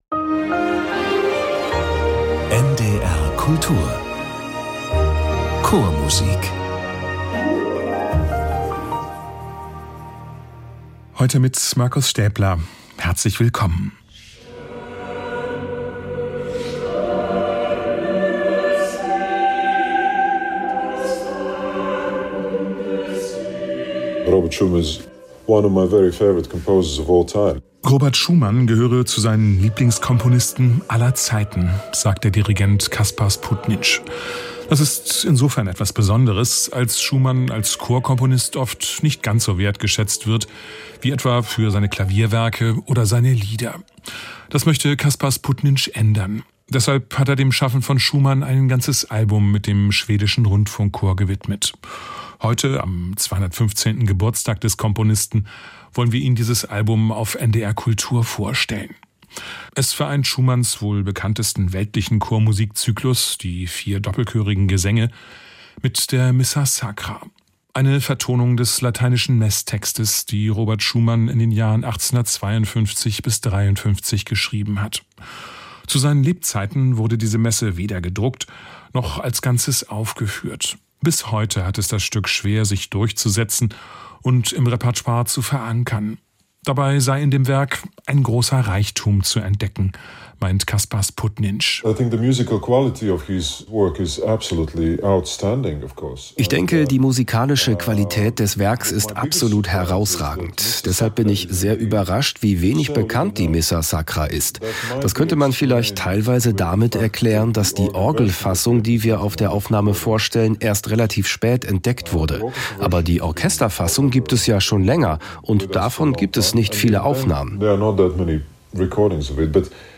Kaspars Putni_š und der Schwedische Rundfunkchor präsentieren die Missa sacra und die Vier doppelchörigen Gesänge von Robert Schumann.